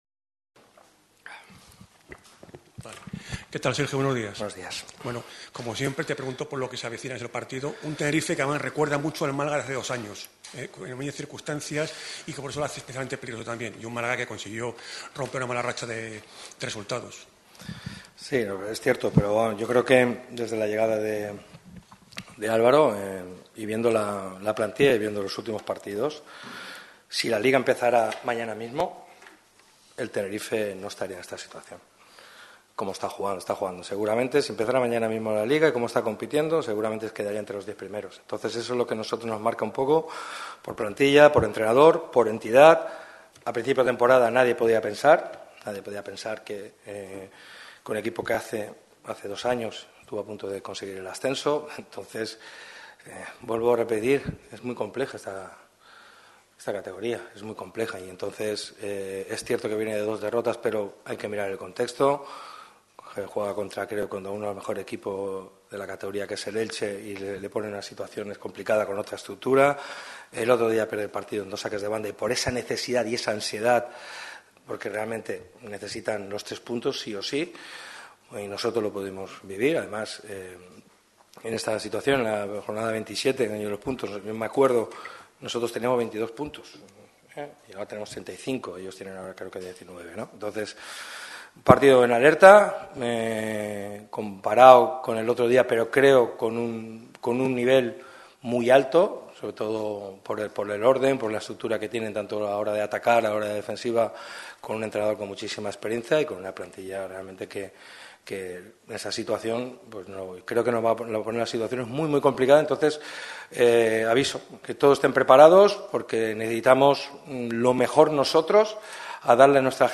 compareció en la sala de prensa del estadio de La Rosaleda en la previa del partido del viernes ante el Tenerife.